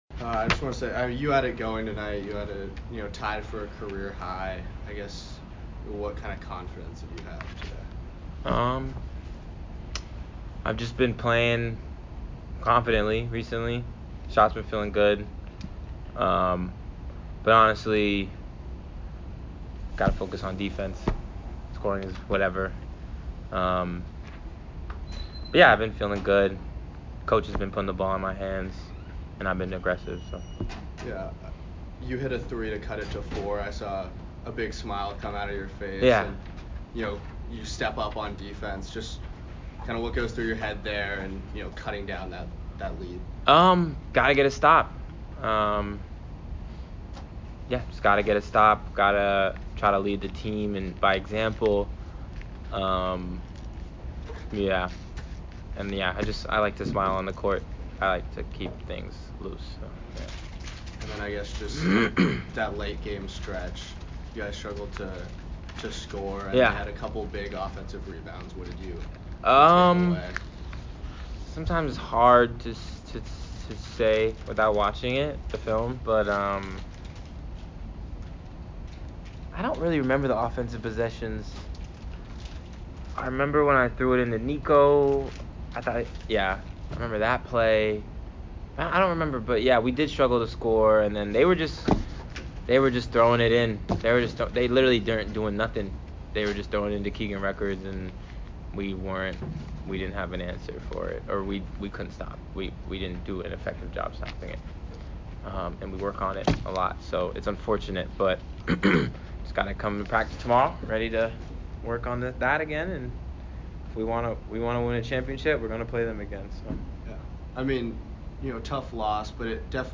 Men's Basketball / Colgate Postgame Interview